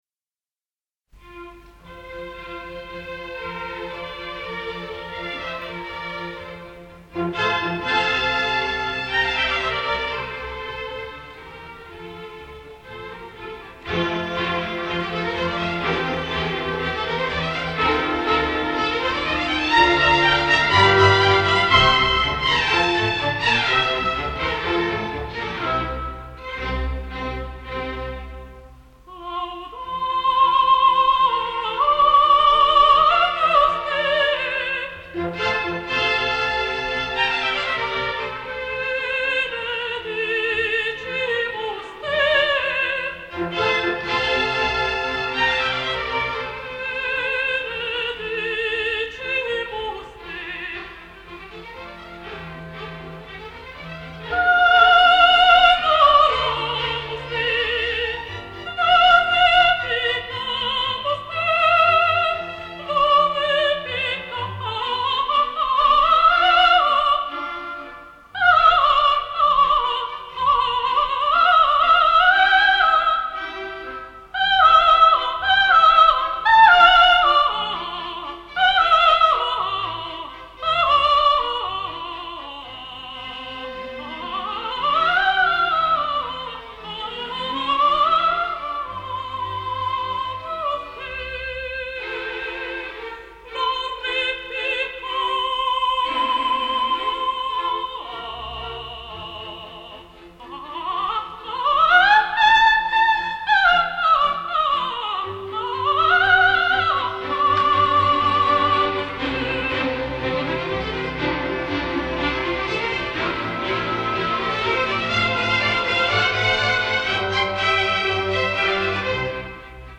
Несколько записей прекрасной румынской певицы меццо-сопрано Елены Черней ( 1924-2000)